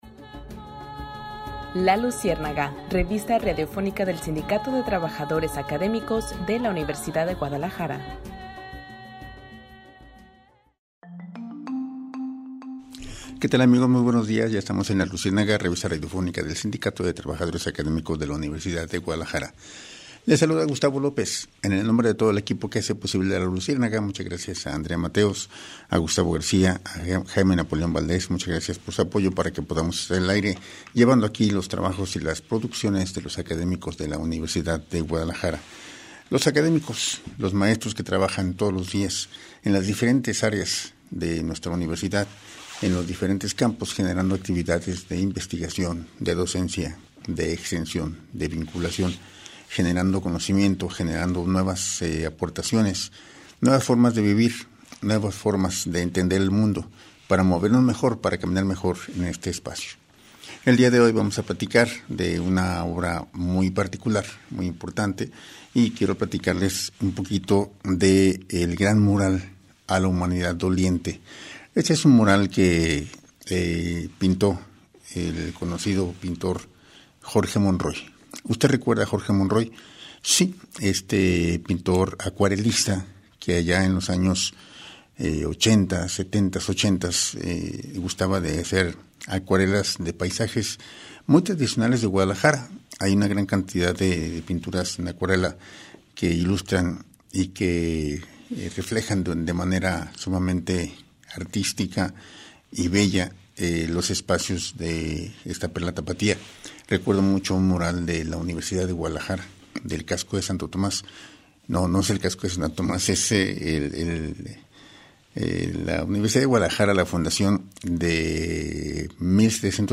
No te pierdas la revista radiofónica del Sindicato de Trabajadores Académicos de la Universidad de Guadalajara.